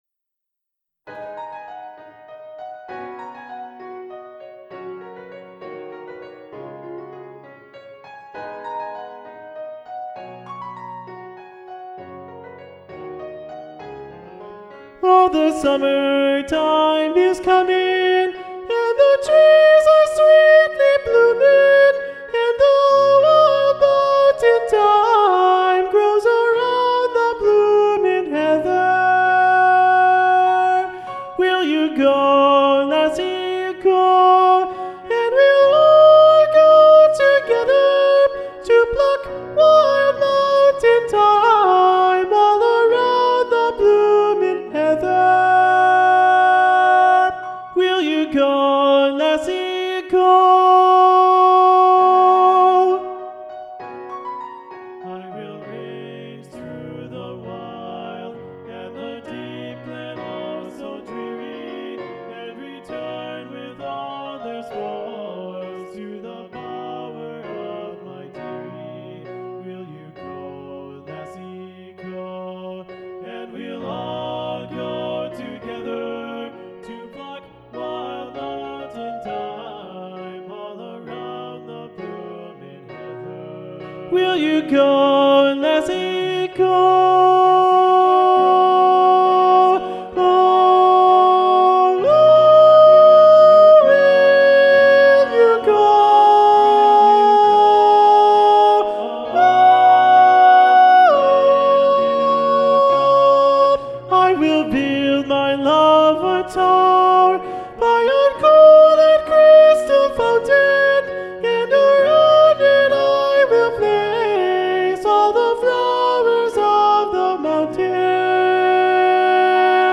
Soprano 1